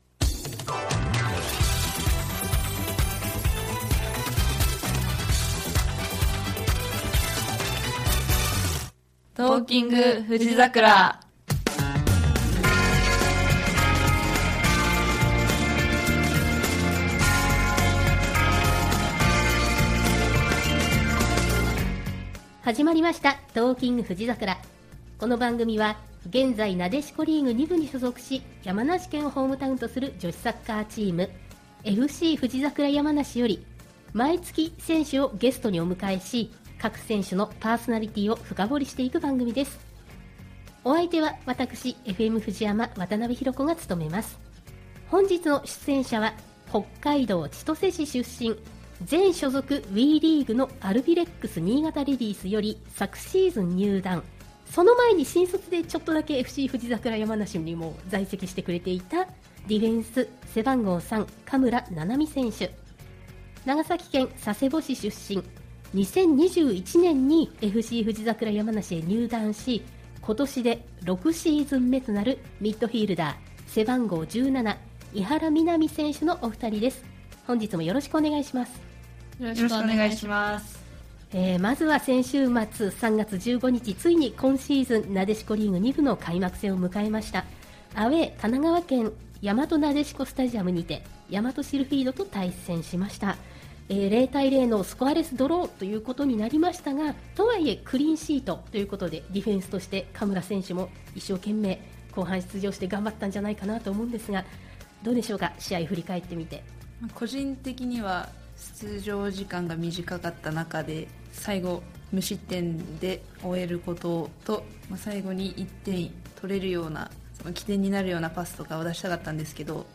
（ラスト曲は版権の都合上カットしています。
ゆっくりとお話をしています。